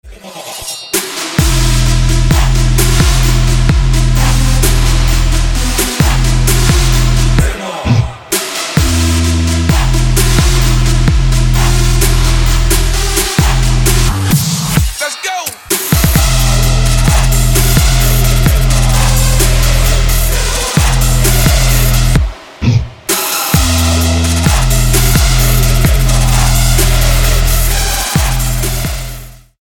Trap
Bass